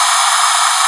rr3-assets/files/.depot/audio/sfx/forced_induction/turbo_02.wav
turbo_02.wav